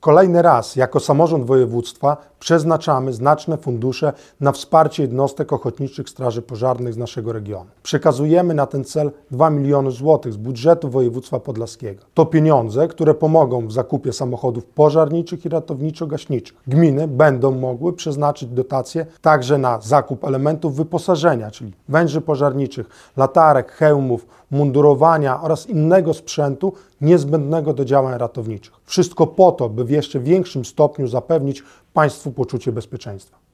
O szczegółach mówi Artur Kosicki, marszałek województwa podlaskiego.